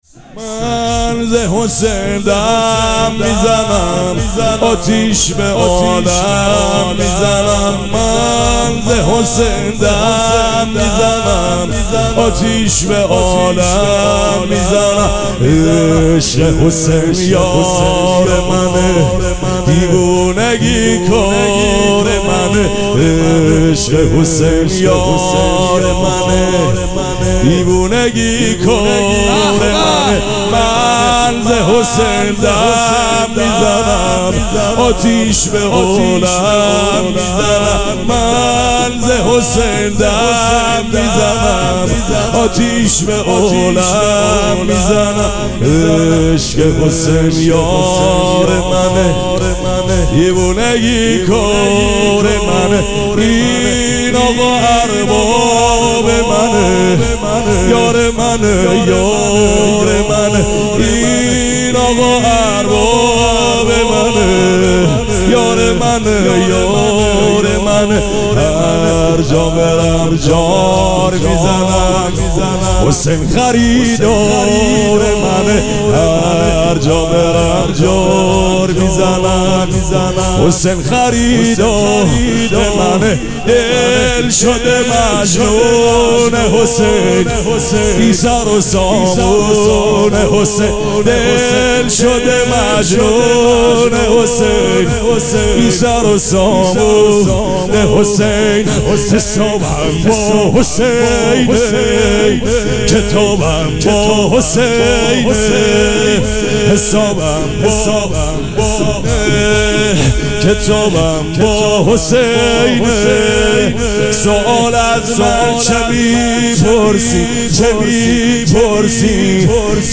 شب پنجم مراسم عزاداری اربعین حسینی ۱۴۴۷
شور
مداح